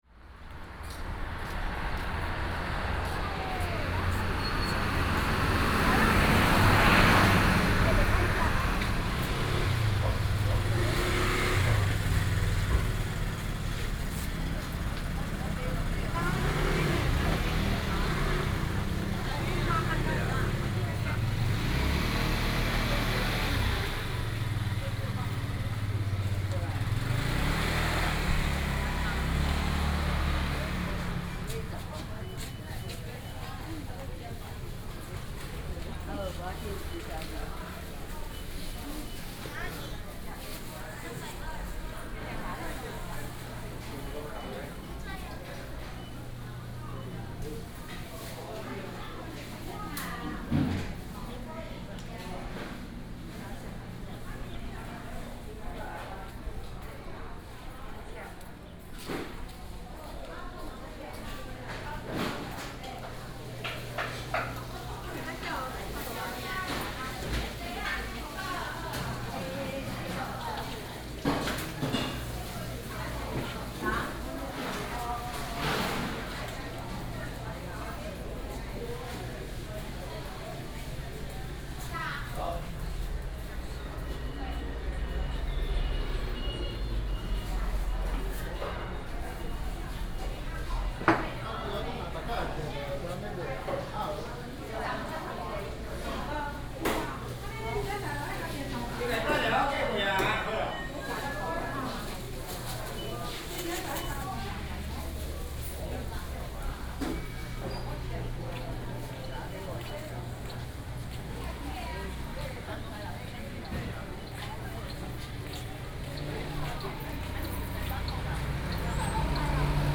山上公有零售市場,Shanshang Dist., Tainan City - Walking in the traditional market
Walking in the traditional market,Traffic Sound,,birds sound,Street vendor,Binaural recordings ,Best with Headphone, Proposal to turn up the volume ,Sony PCM D100+ Soundman OKM II